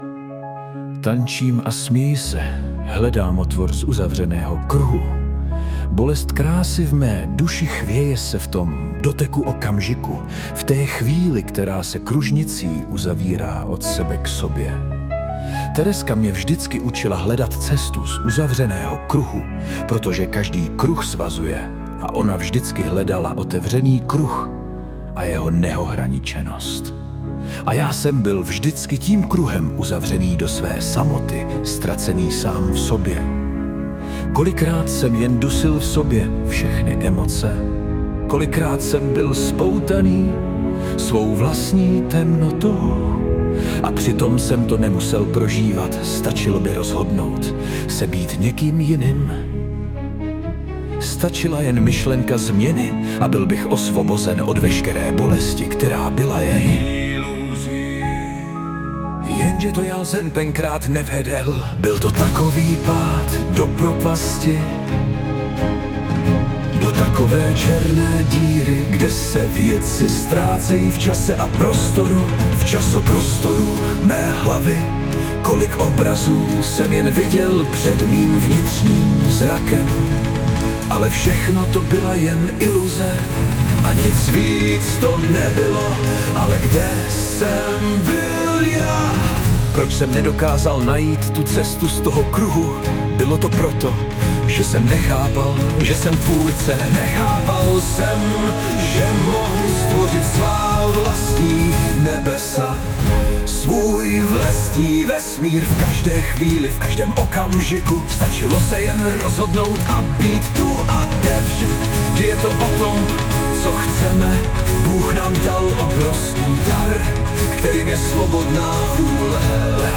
2002 & Hudba a Zpěv: AI